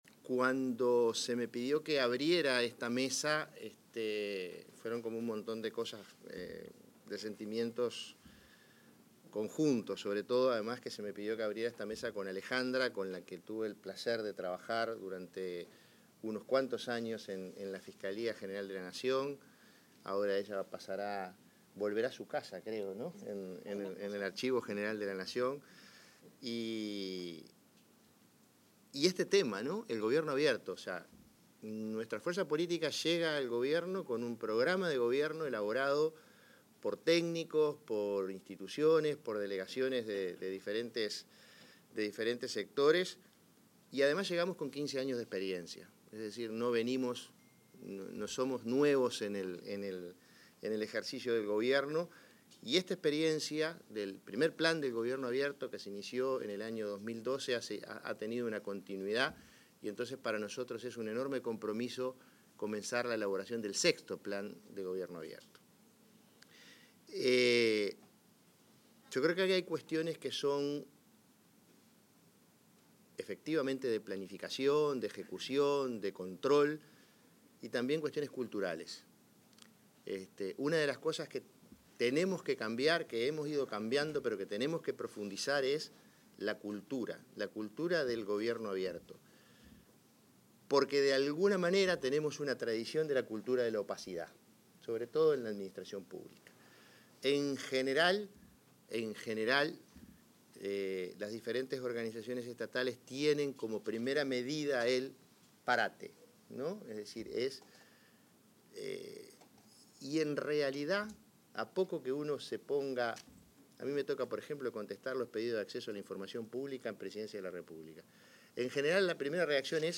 Palabras del prosecretario de la Presidencia, Jorge Díaz
El prosecretario de la Presidencia, Jorge Díaz, expuso en la apertura de la primera mesa de diálogo en el marco del proceso de creación del Sexto Plan